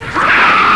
zo_pain2.wav